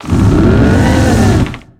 Cri de Desséliande dans Pokémon X et Y.